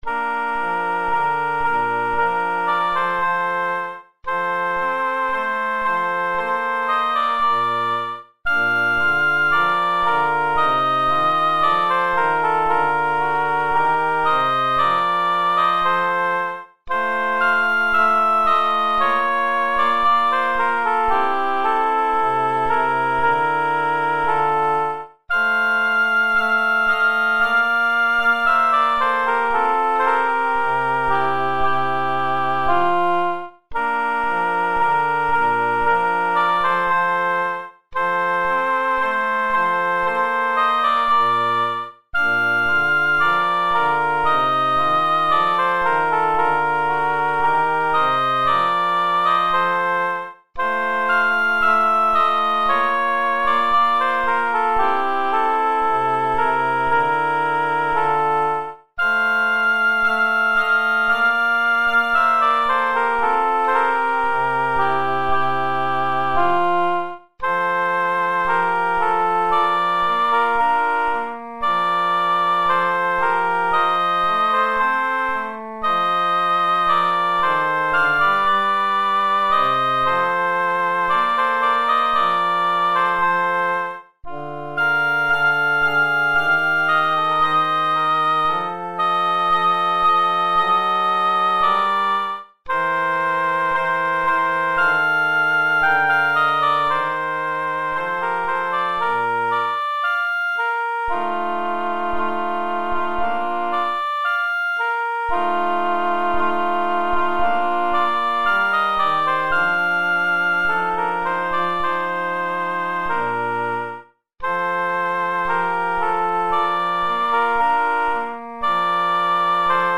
piu_non_si_trovano_soprani.mp3